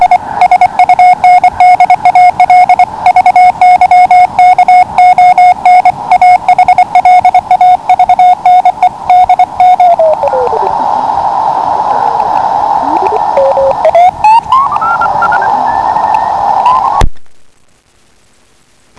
Jinak kvalitu tónu posuzují posluchači jako vynikající. Kliksy nejsou zřetelné.